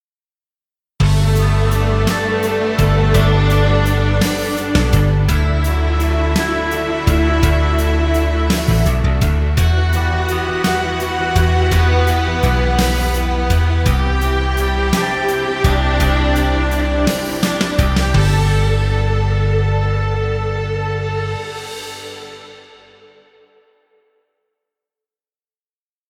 Background Rock music.